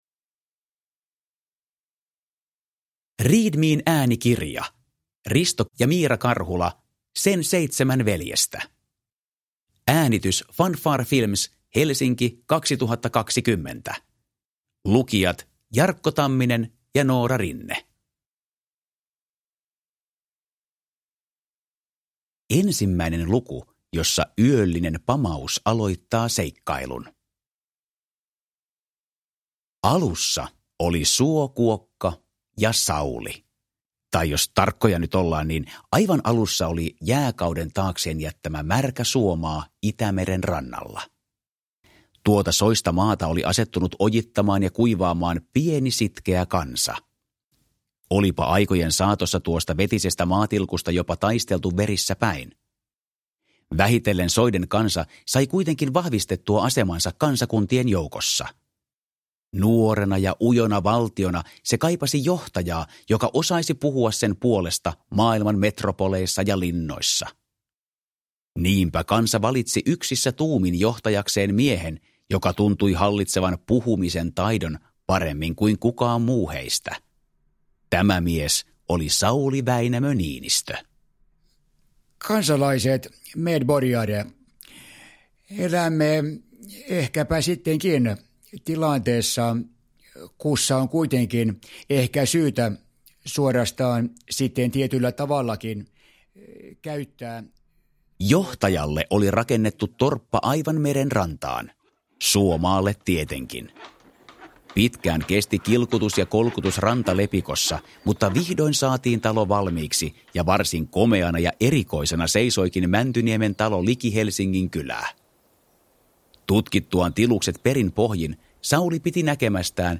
Sen seitsemän veljestä (ljudbok